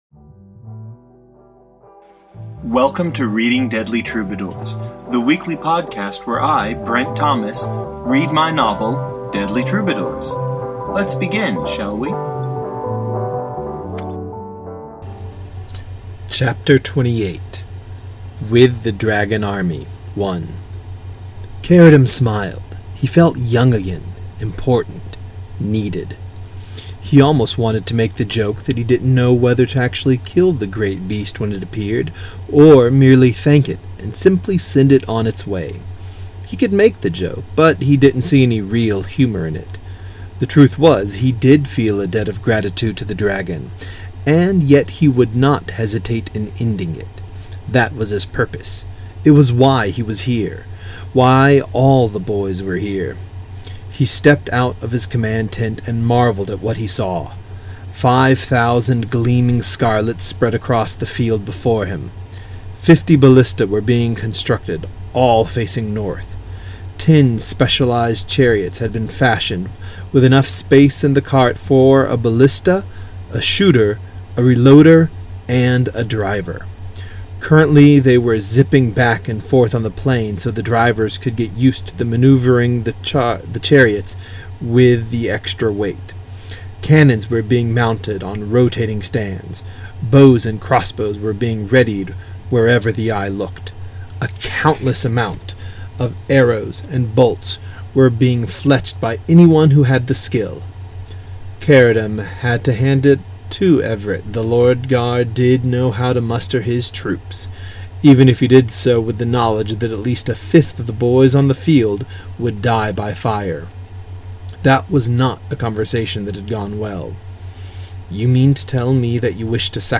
reads the twenty-eighth chapter of Deadly Troubadours